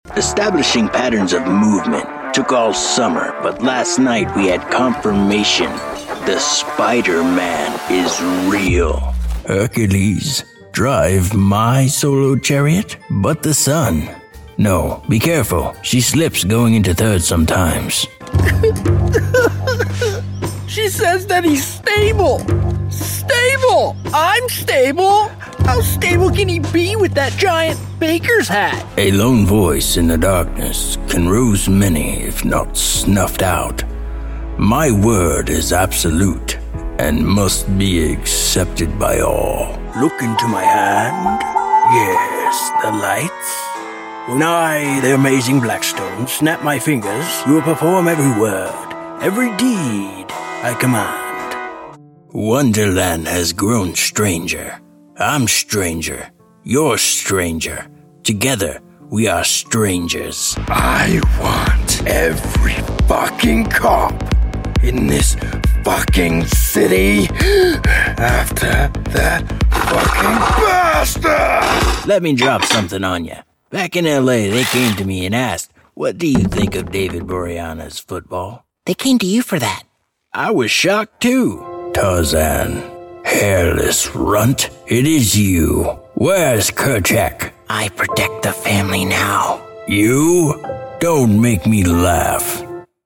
voz de barítono dinámica y áspera con un estilo auténtico y conversacional, ideal para anuncios, narraciones y personajes.
Animación
Tengo un estudio de grabación profesional en casa, así como sólidas habilidades de mezcla y masterización.